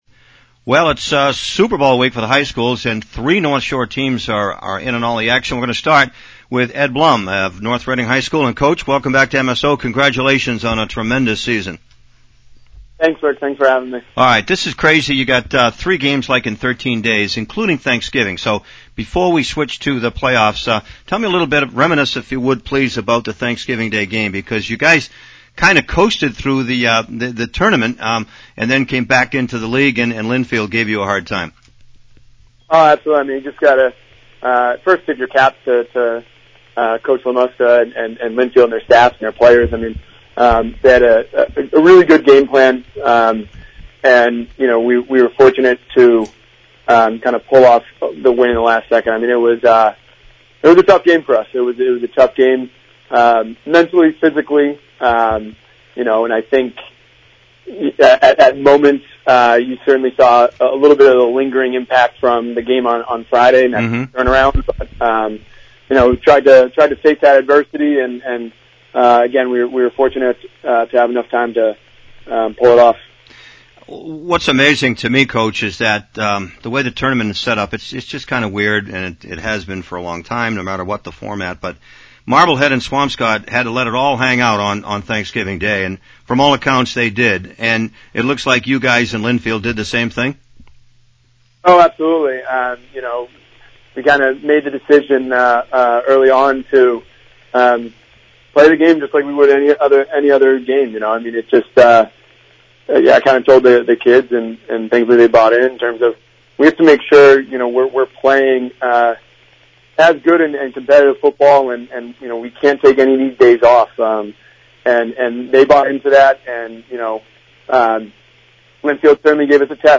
Post-game, Pre-game